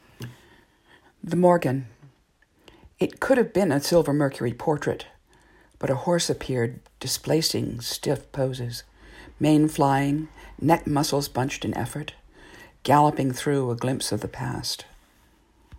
Audio: Read by the author.